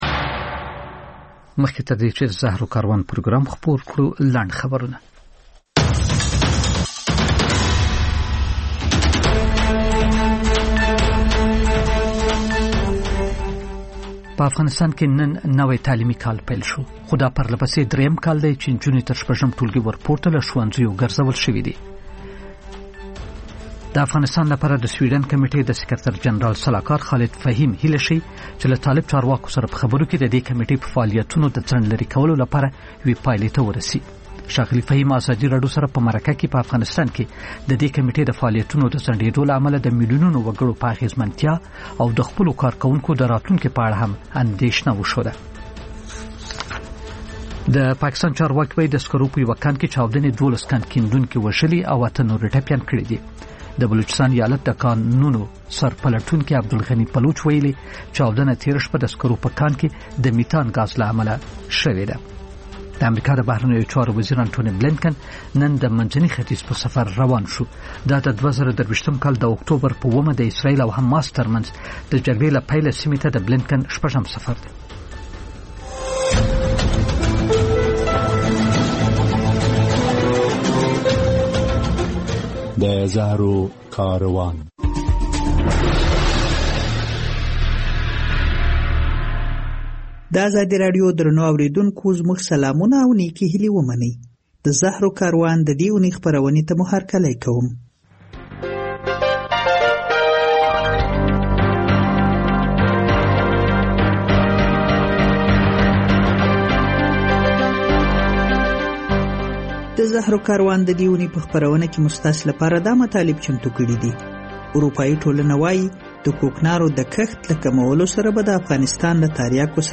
لنډ خبرونه - د زهرو کاروان (تکرار)